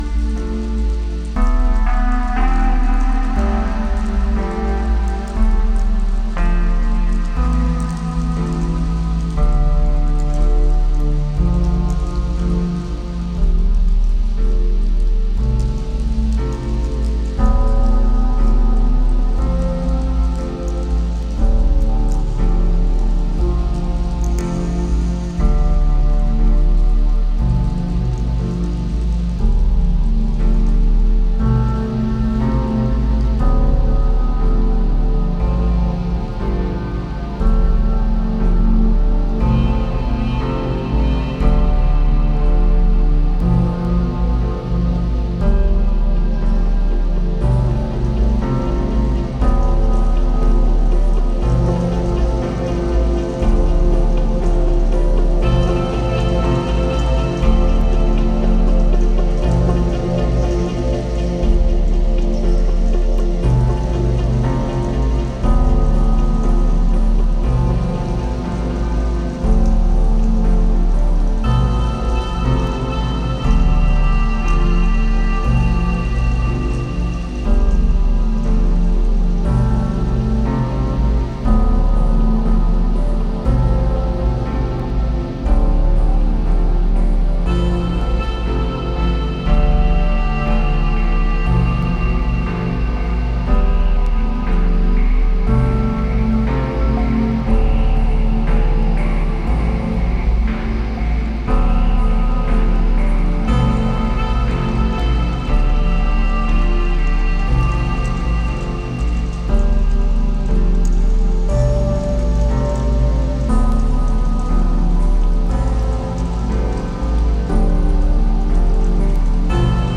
Simply another masterpiece. 1 Samples Ambient